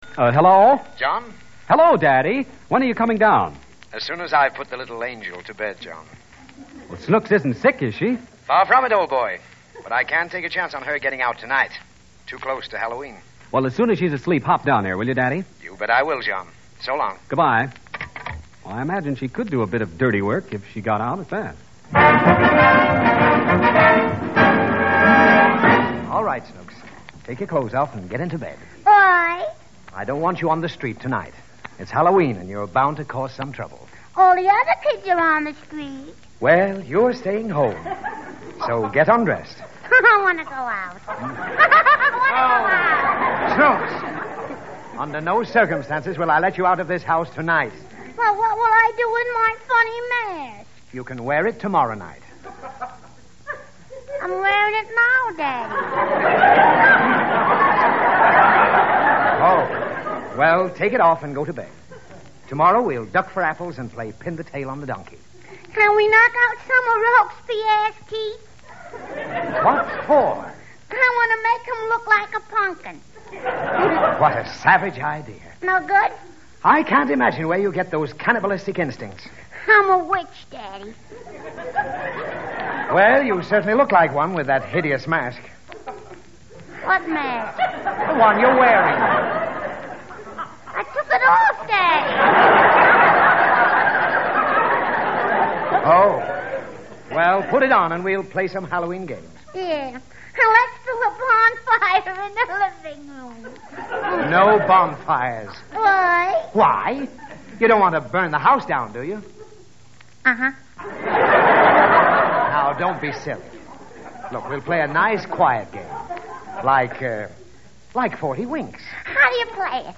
The Baby Snooks Radio Program, Starring Fanny Brice